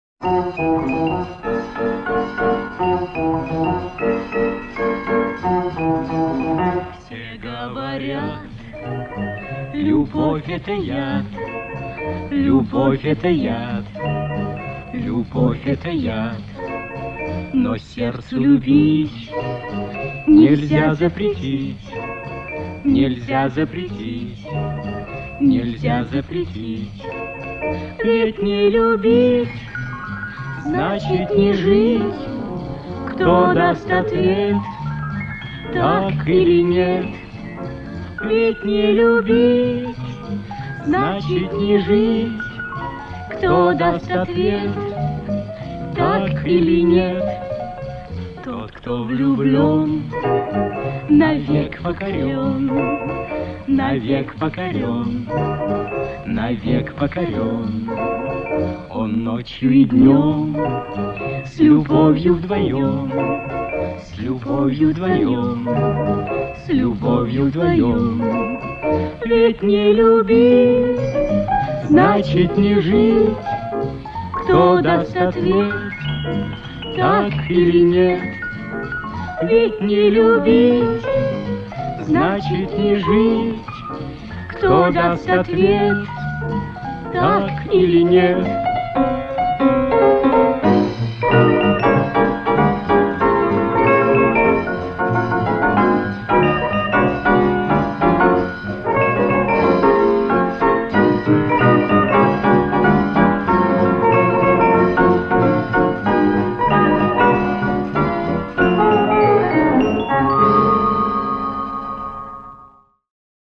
• обработанный Standart Hiss Reduction